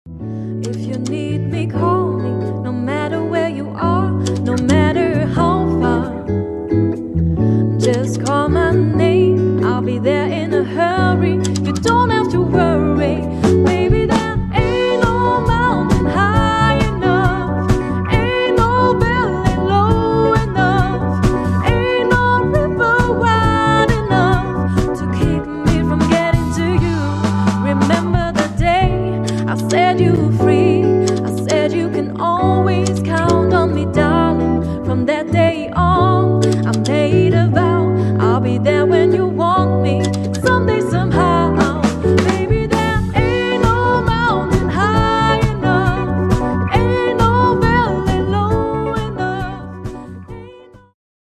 QUINTETT
voc/piano/git/bass/drums